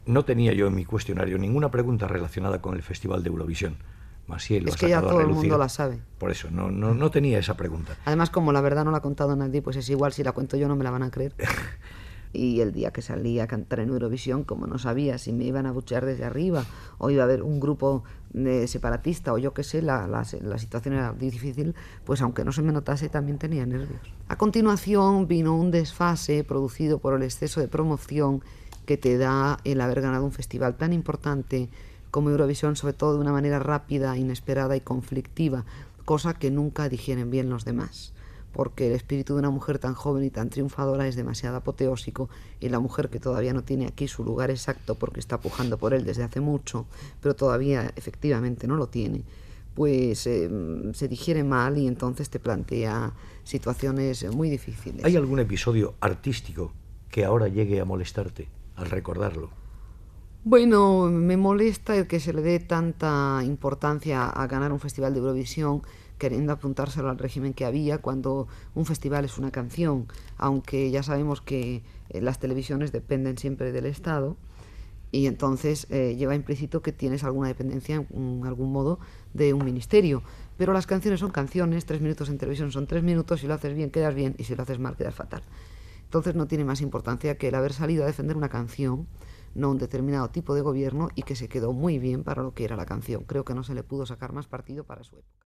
Entrevista a la cantant Massiel (María de los Ángeles Félix Santamaría), guanyadora del Festival d'Eurovisió a l'any 1968. Explica les conseqüències que va tenir aquell triomf